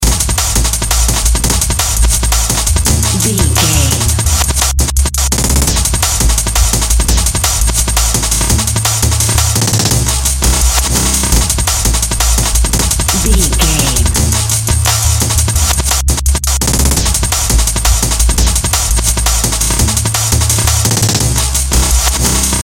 Atonal
Fast
hypnotic
industrial
driving
energetic
frantic
dark
drum machine
synthesiser
Drum and bass
break beat
electronic
sub bass
synth leads
synth bass